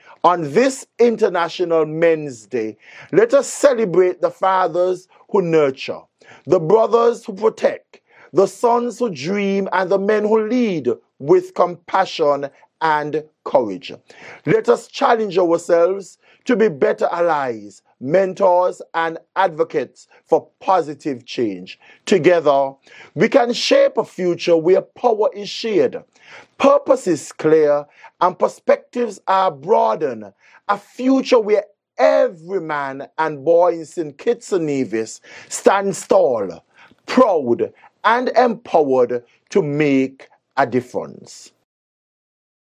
Deputy Prime Minister and Minister of Gender Affairs-St. Kitts, the Hon. Dr. Geoffrey Hanley shared this message in an address:
Deputy Prime Minister, Dr. Geoffrey Hanley.